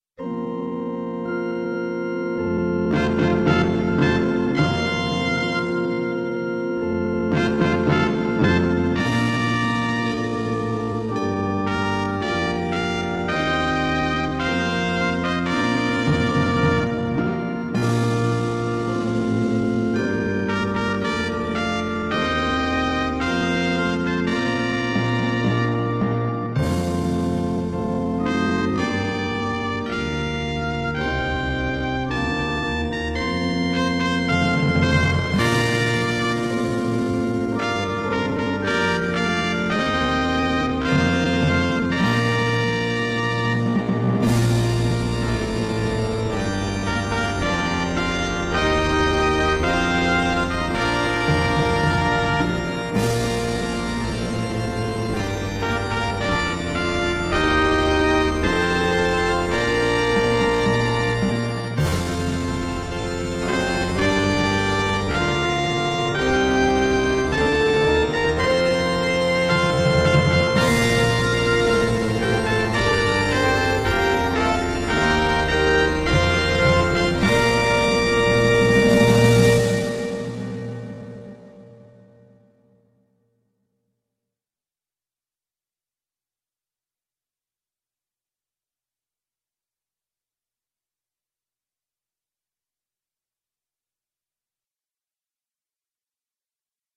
Processional (slow) acc.mp3